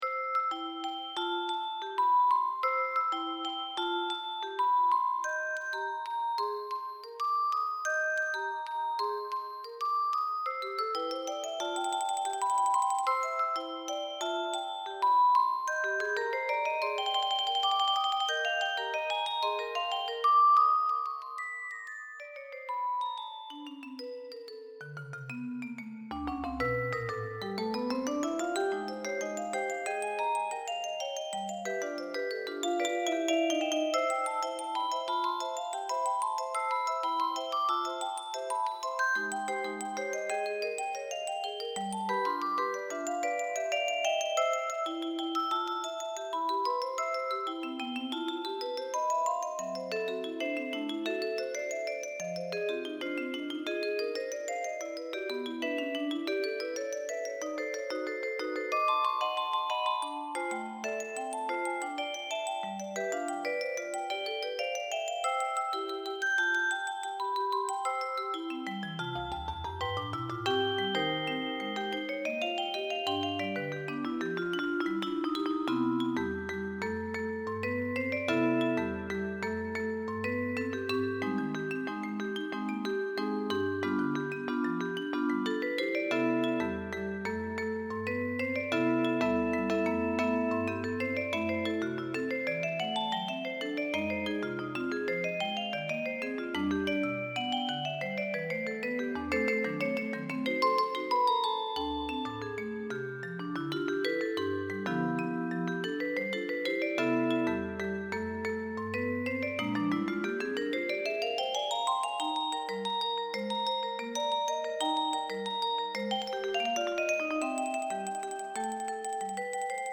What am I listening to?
Instrumentation: percussions